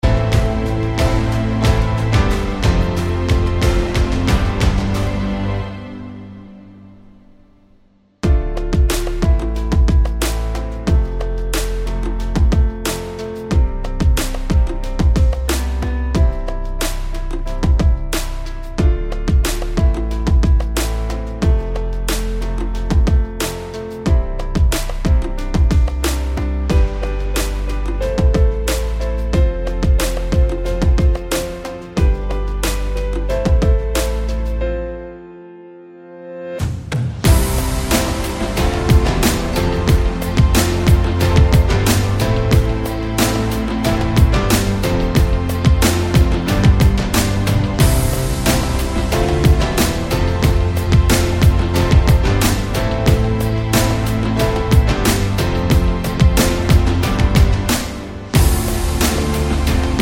Pop (2020s) 3:08 Buy £1.50